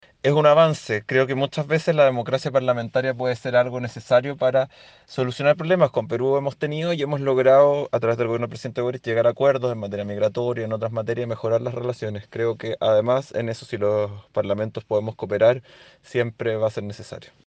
El diputado de la bancada Socialista y presidente de la comisión de Relaciones Exteriores de la Cámara en Chile, Tomás de Rementería, valoró la instancia de reunión entre los representantes de ambos parlamentos.